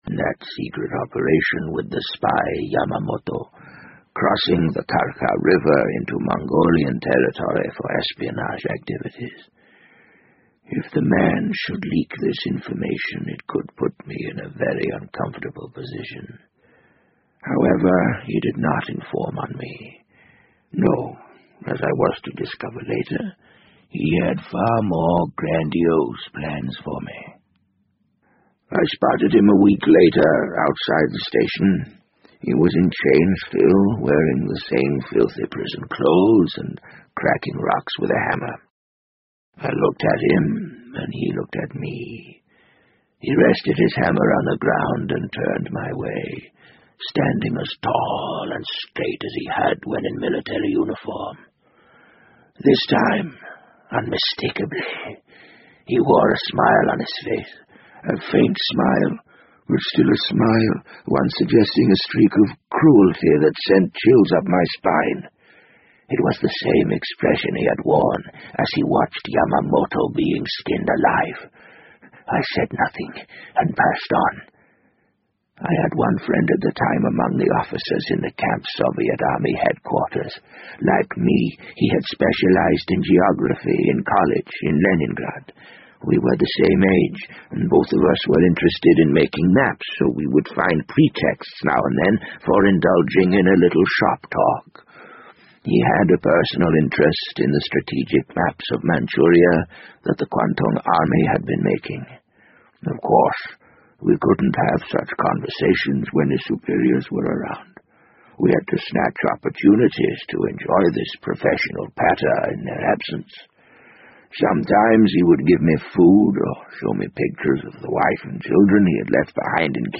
BBC英文广播剧在线听 The Wind Up Bird 014 - 5 听力文件下载—在线英语听力室